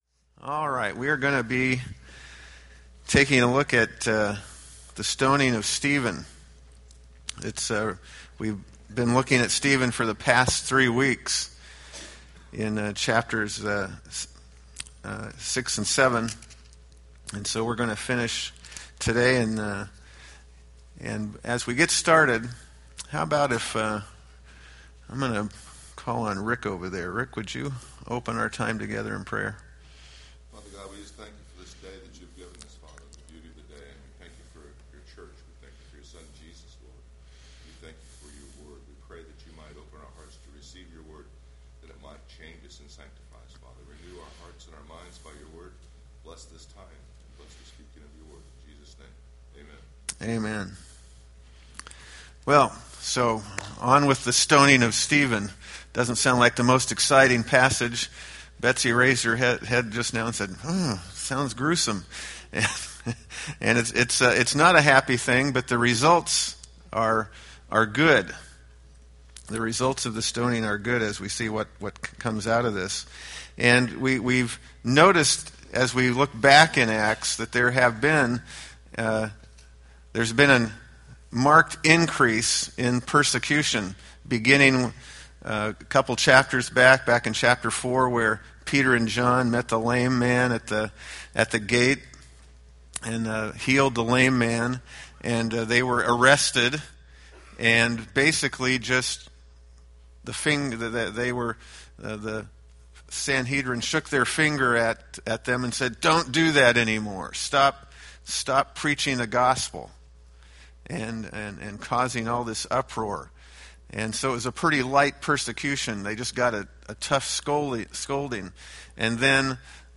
Date: Mar 9, 2014 Series: Acts Grouping: Sunday School (Adult) More: Download MP3